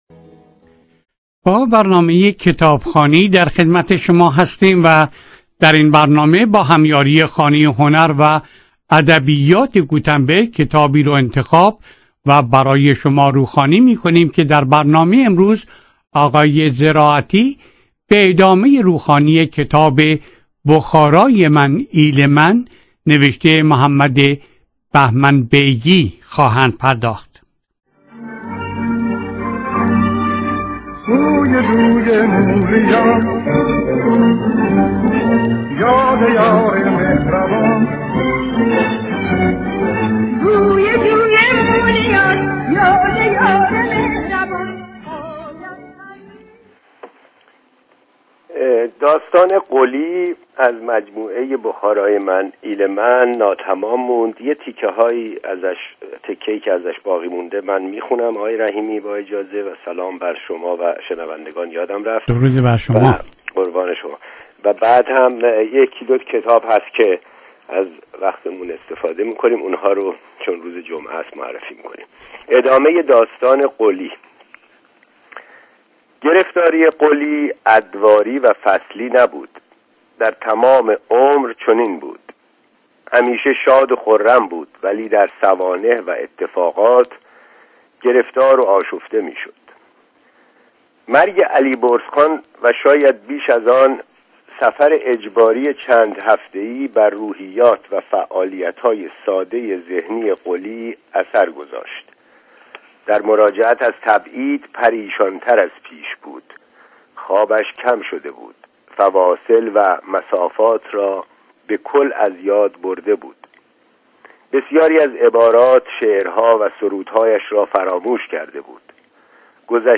در برنامه کتابخوانی رادیو سپهر در 24 بخش روخوانی شد